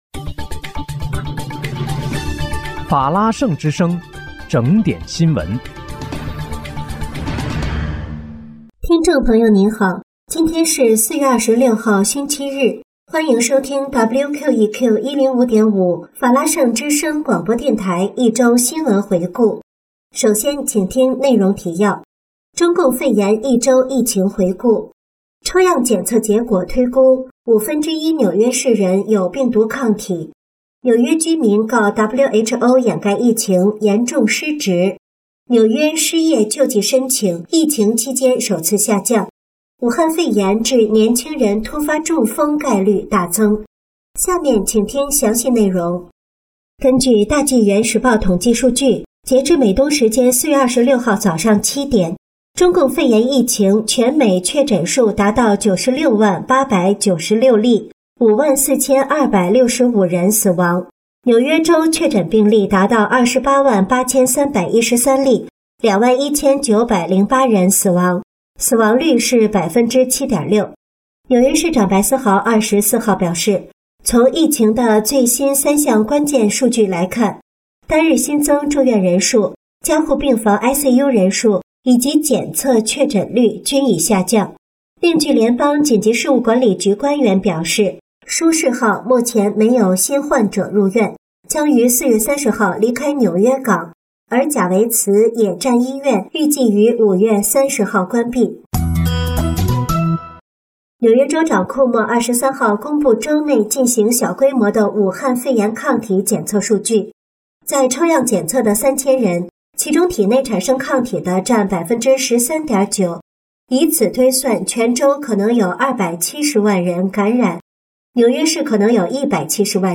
4月26日（星期日）一周新闻回顾